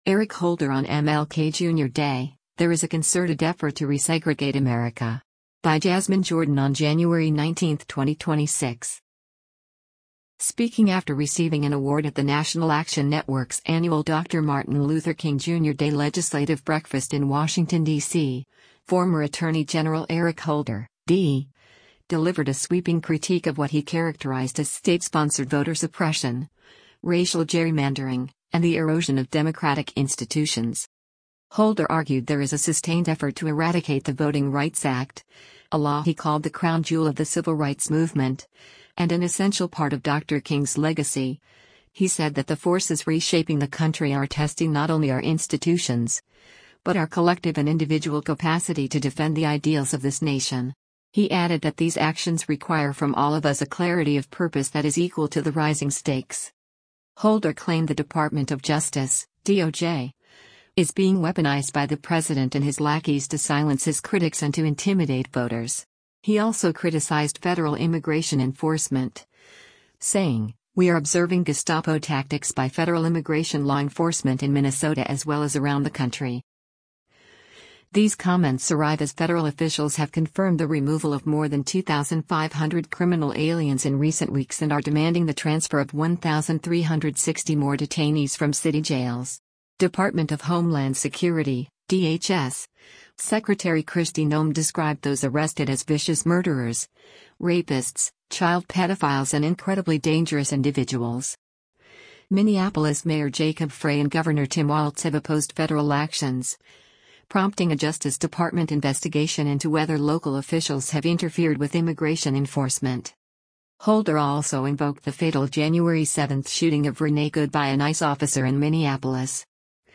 Speaking after receiving an award at the National Action Network’s annual Dr. Martin Luther King Jr. Day Legislative Breakfast in Washington, DC, former Attorney General Eric Holder (D) delivered a sweeping critique of what he characterized as state-sponsored voter suppression, racial gerrymandering, and the erosion of democratic institutions.